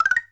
powerup_pickup.wav